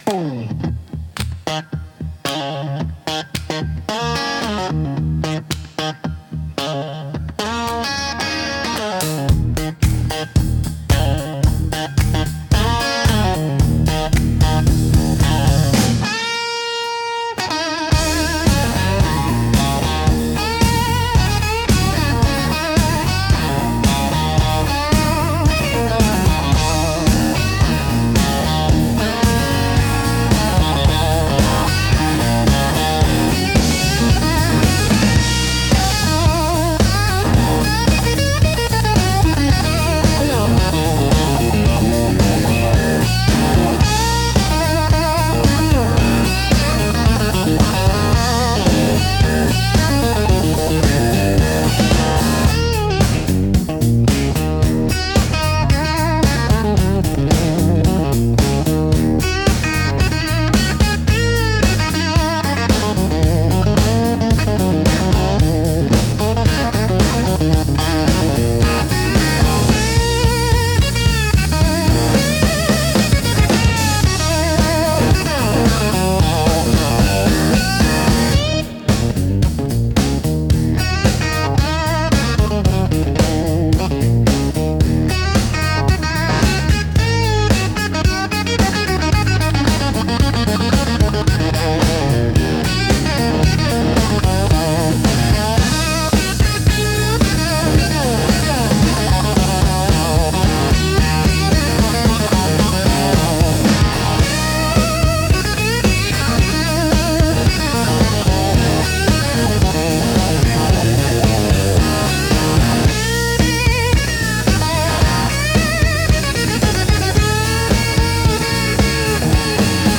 Instrumental - Where the Highways Hum